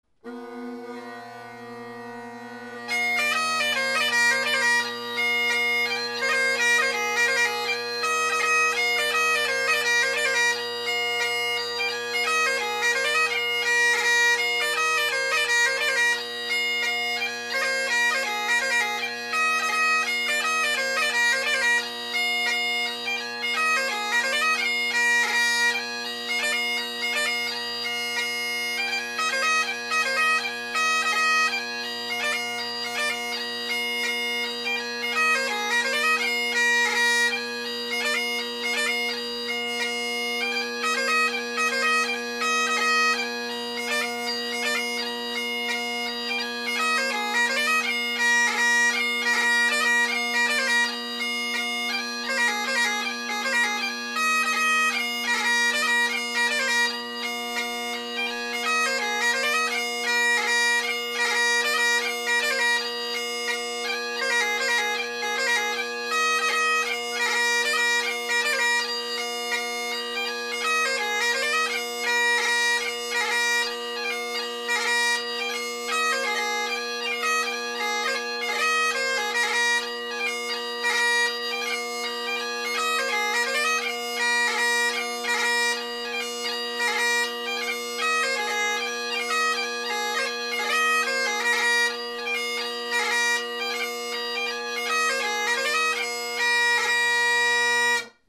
Bagpipe
Clachnacuddin Hornpipe – Same as above but different prototype chanter and different Murray Henderson reed (how consistent), last two notes in the 4th bar of the 4th part a wee bit different